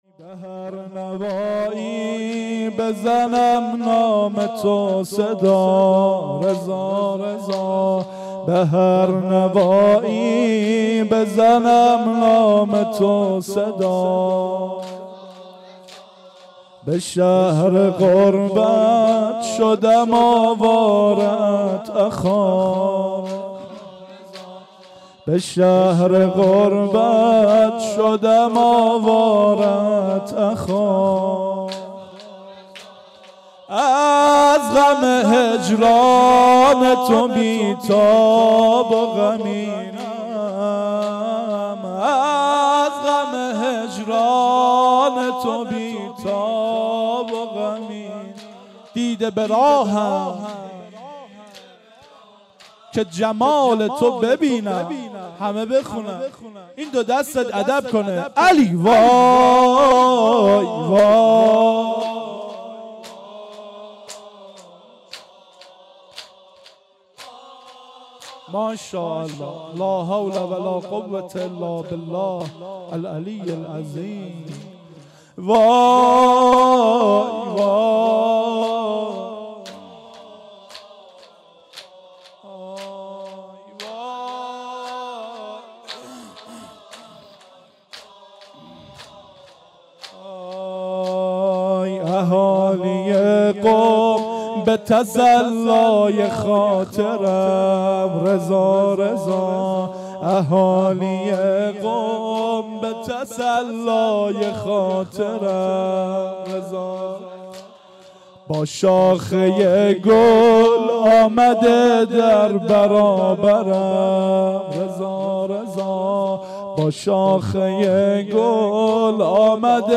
Shahadate-H.Masoumeh-94-Zamineh.mp3